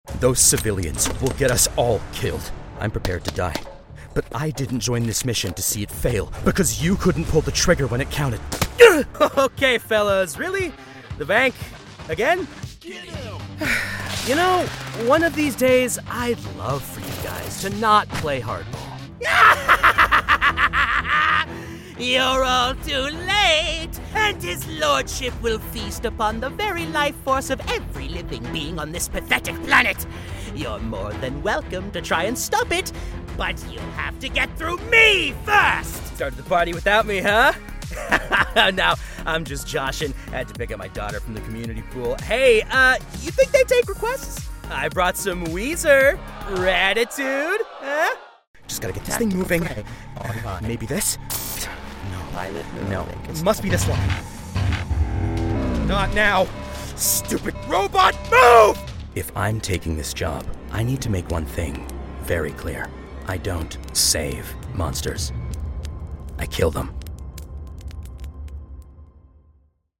Inglés (Cánada)
Animación
En cuanto a mi estudio casero, utilizo un micrófono U87 conectado a una interfaz Universal Audio Volt. Mi cabina está completamente tratada con paneles acústicos fabricados con Rockwool Safe n' Sound.
Adulto joven
Barítono
InformativoAmistosoConversacionalConfiadoCálido